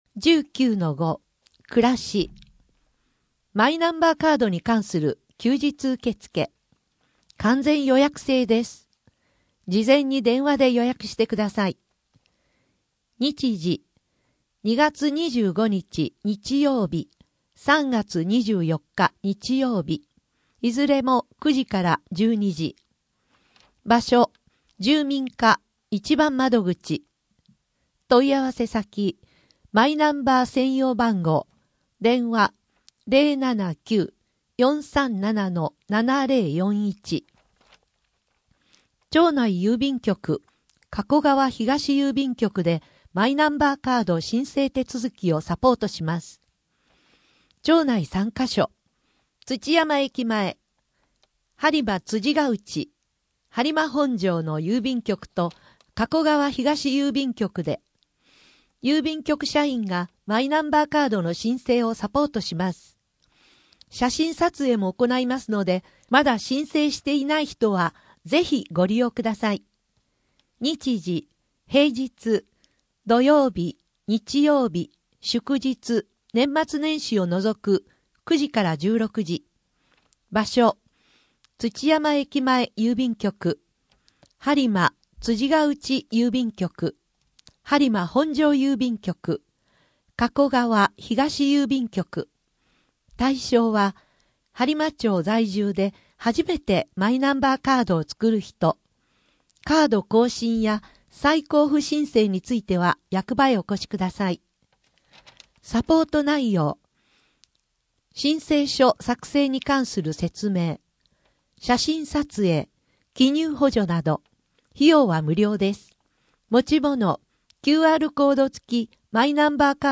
声の「広報はりま」2月号
声の「広報はりま」はボランティアグループ「のぎく」のご協力により作成されています。